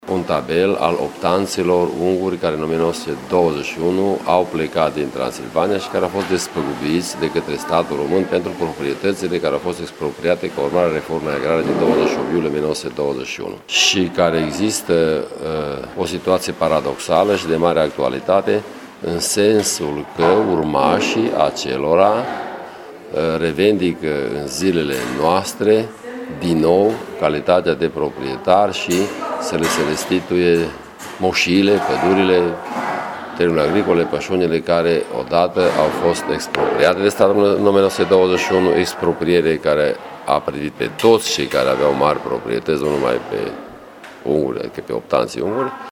Una dintre problemele importante dezbătute în această după-masă la Universitatea de Vară de la Izvoru Mureşului este cea legată de procesele de retrocedare a proprietăţilor.